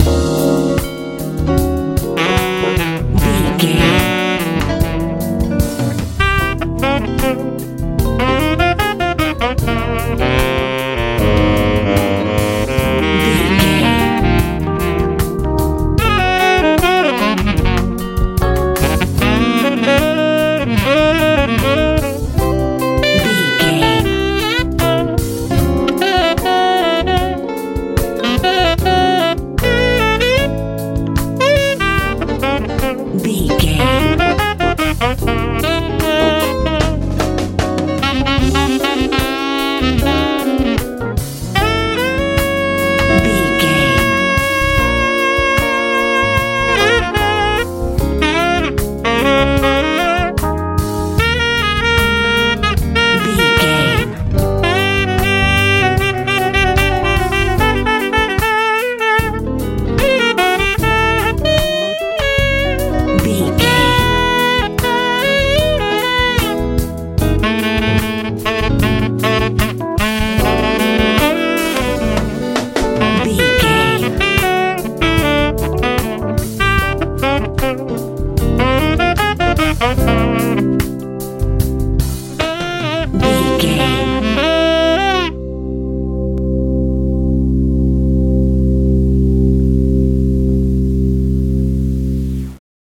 Ionian/Major
groovy
drums
bass guitar
horns
organ
sensual
smooth
relaxed